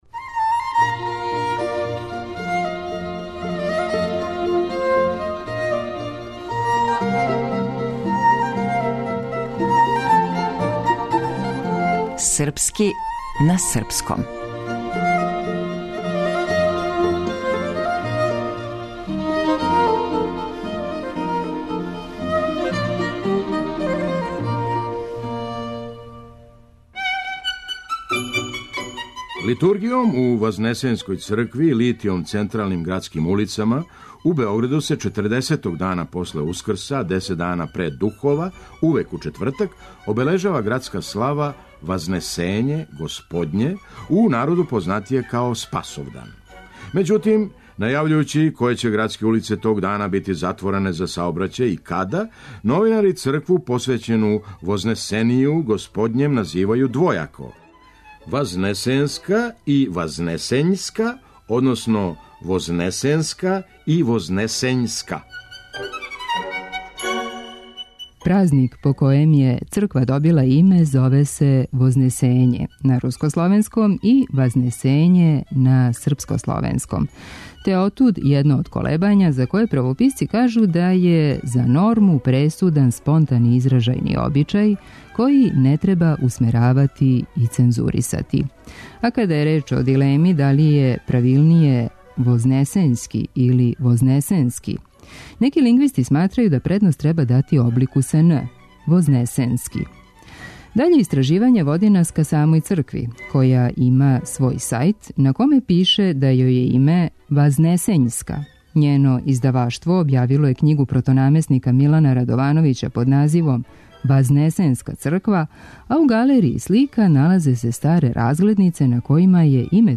Драмски уметник: Феђа Стојановић.